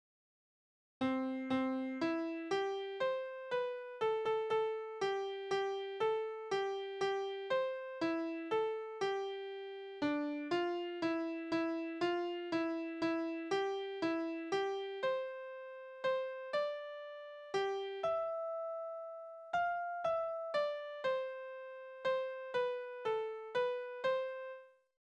Dialoglieder: Der betrunkene Besenbinder
Tonart: C-Dur
Taktart: 3/4
Tonumfang: Oktave, Quarte
Besetzung: vokal